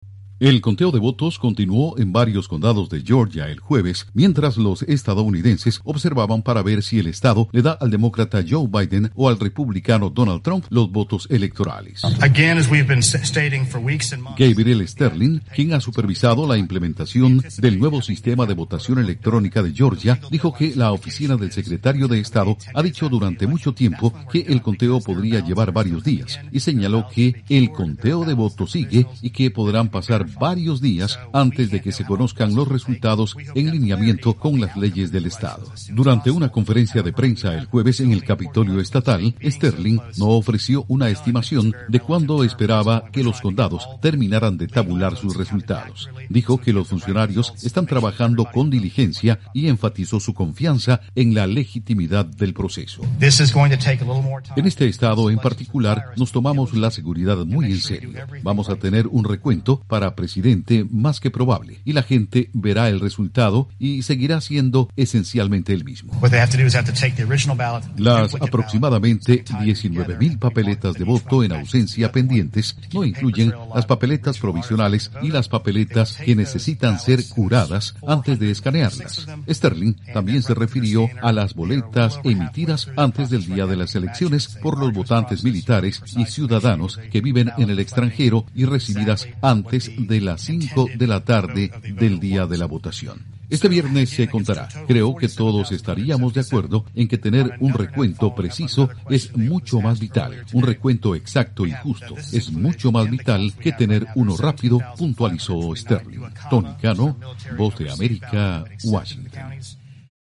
Informa desde la Voz de América en Washington